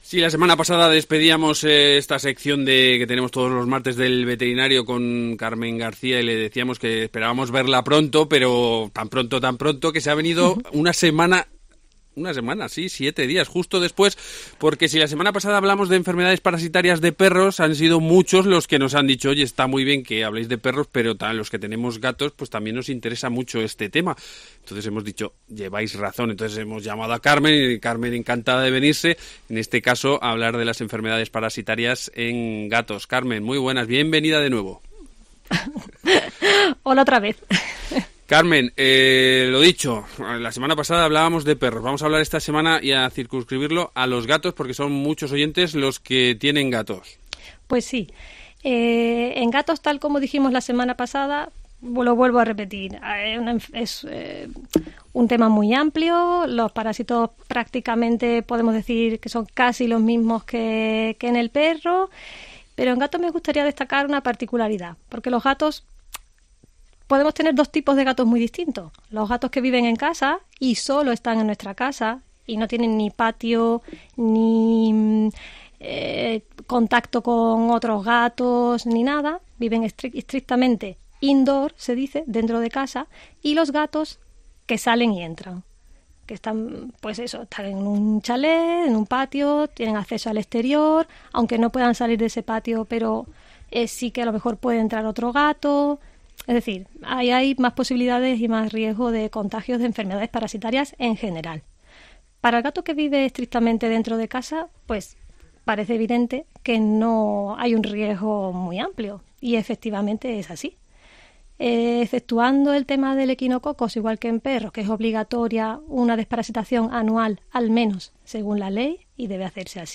veterinaria